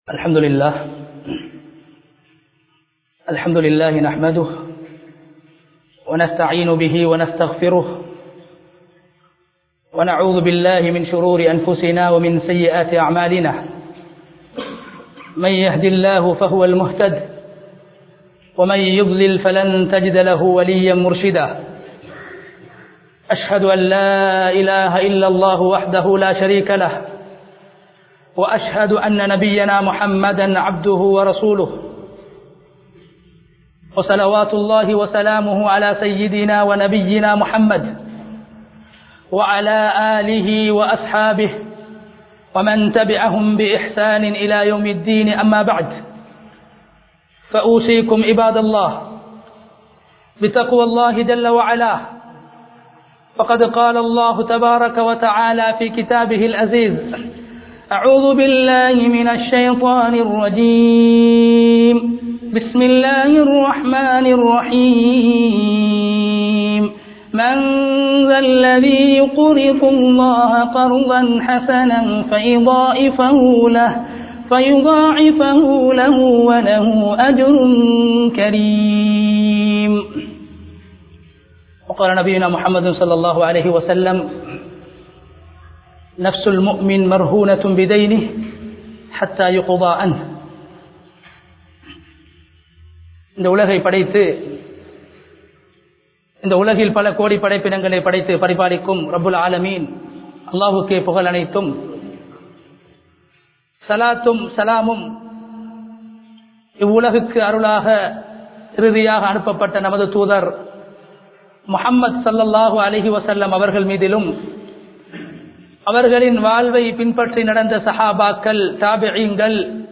Kadan (கடன்) | Audio Bayans | All Ceylon Muslim Youth Community | Addalaichenai
Kurundugolla Jumuaha Masjith